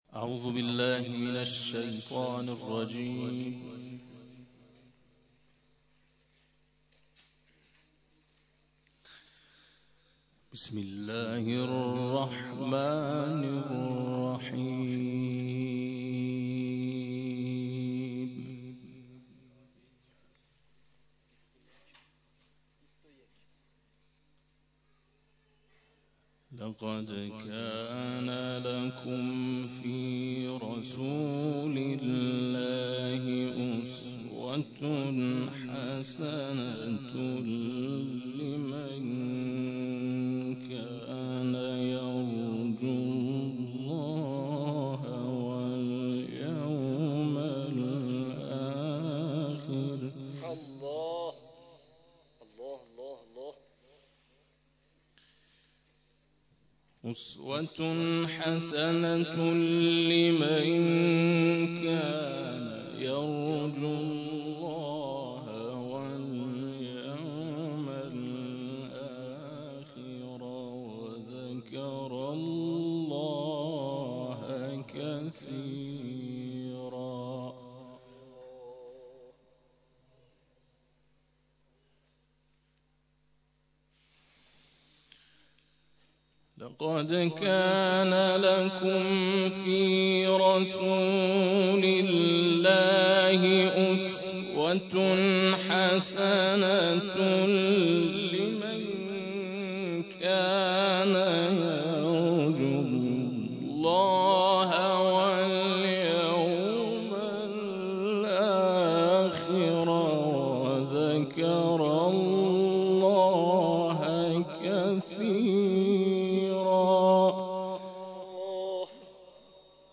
تلاوت سوره احزاب
در مسجد خلیل‌الله
قاری بین‌المللی کشورمان